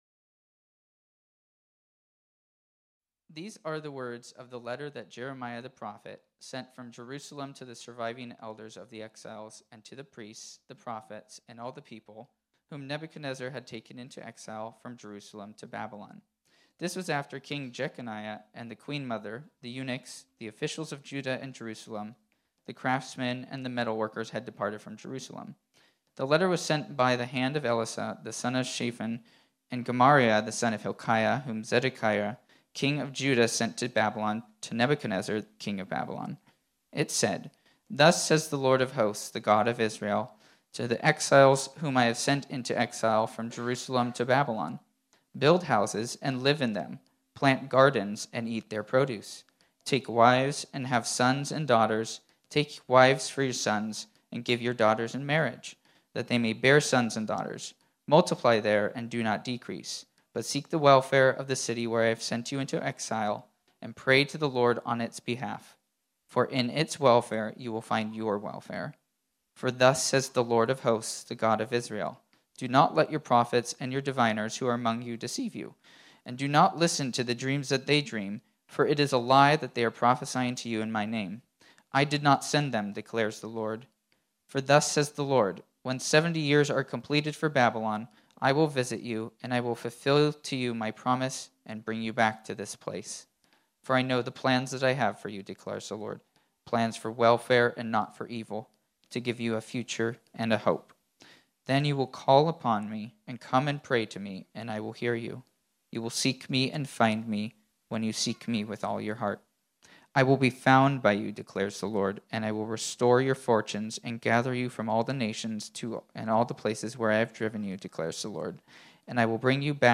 This sermon was originally preached on Sunday, July 3, 2022.